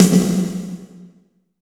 34 BIG SNR-R.wav